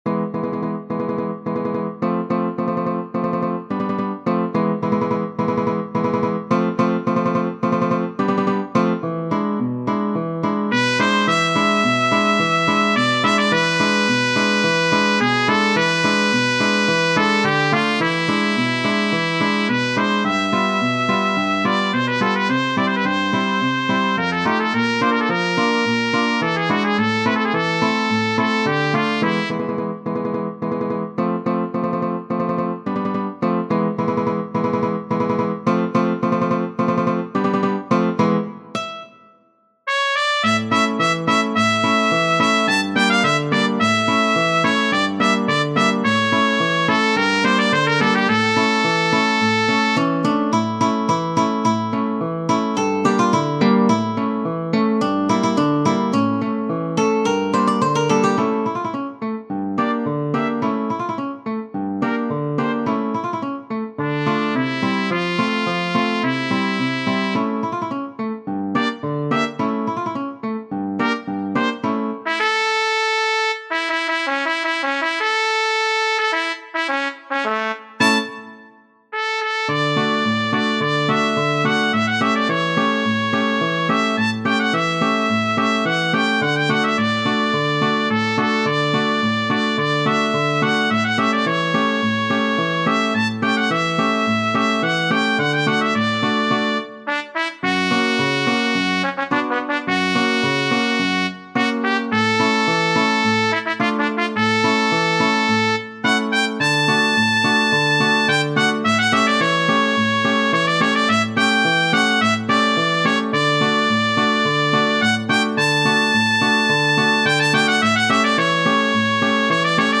Genere: Ballabili
pasodoble